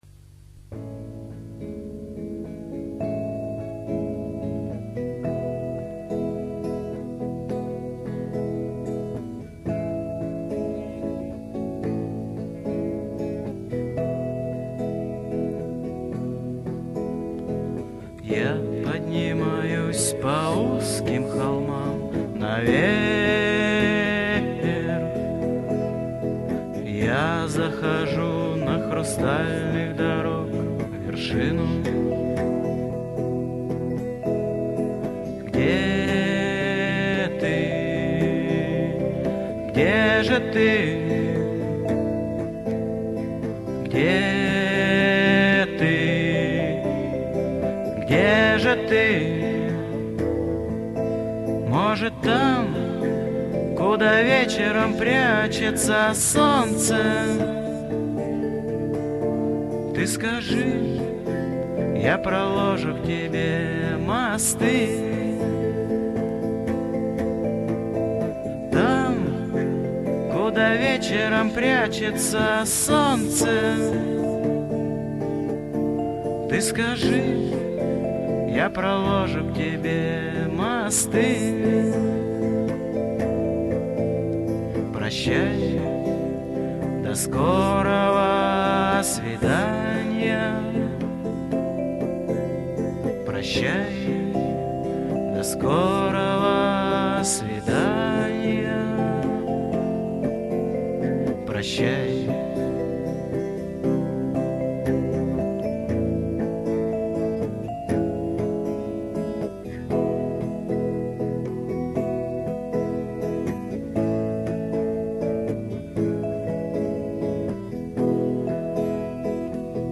Все песни исполняет автор